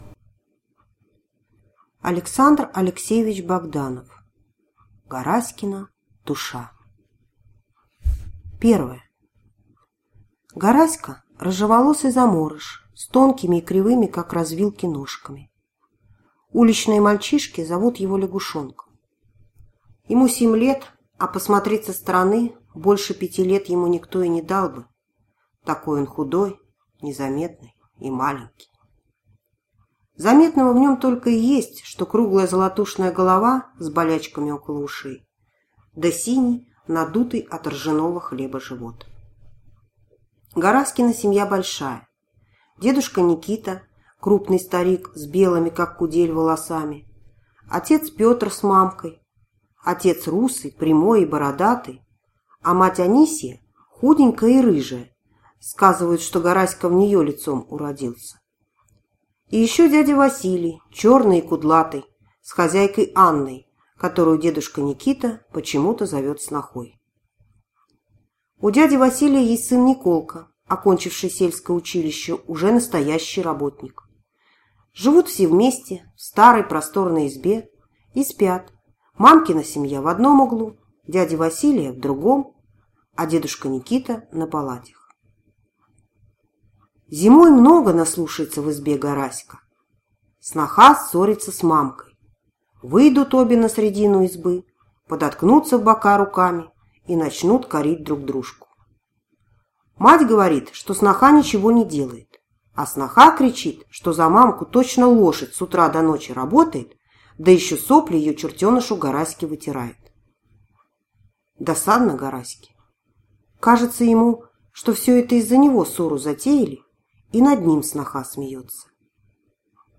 Аудиокнига Гараськина душа | Библиотека аудиокниг